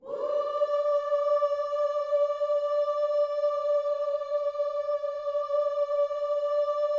WHOO D 5A.wav